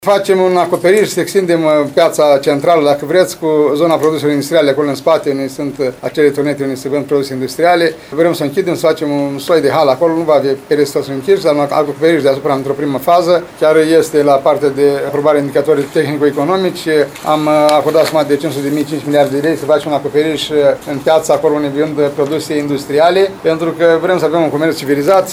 Primarul ION LUNGU a precizat că, la Piața Centrală, se va monta un acoperiș pentru comercianții de produse industriale.